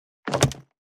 440物を置く,バックを置く,荷物を置く,トン,コト,ドサ,ストン,ガチャ,ポン,タン,スッ,ゴト,カチャ,
効果音室内物を置く